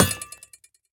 Minecraft Version Minecraft Version snapshot Latest Release | Latest Snapshot snapshot / assets / minecraft / sounds / item / trident / ground_impact2.ogg Compare With Compare With Latest Release | Latest Snapshot
ground_impact2.ogg